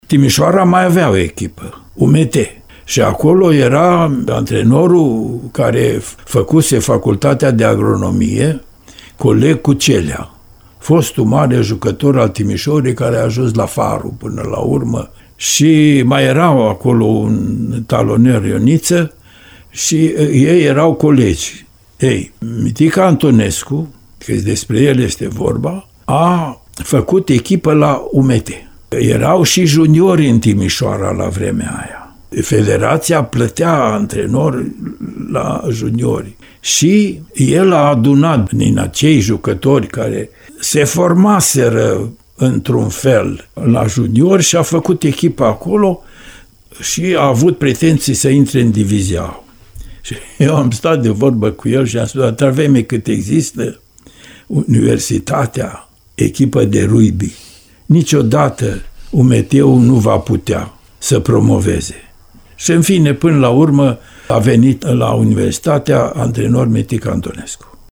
Interviu-serial